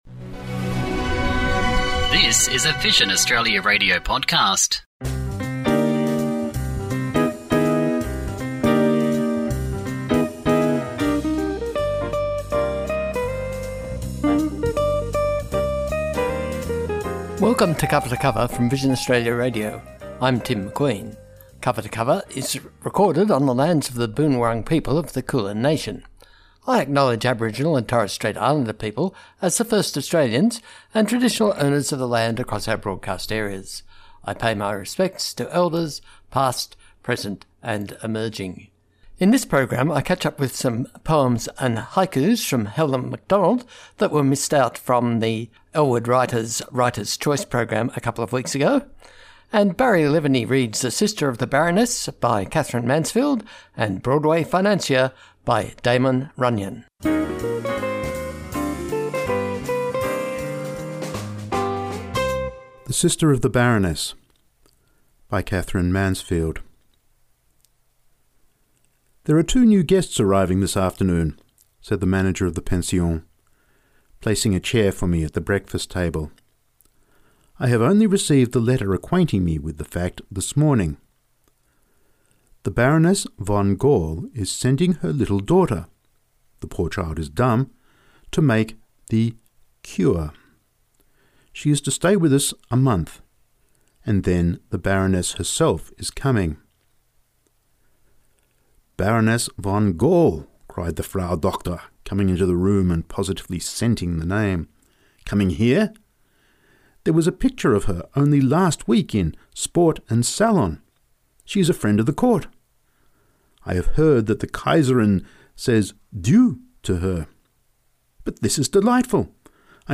Cover to Cover is produced in the studios of Vision Australia Radio in Kooyong.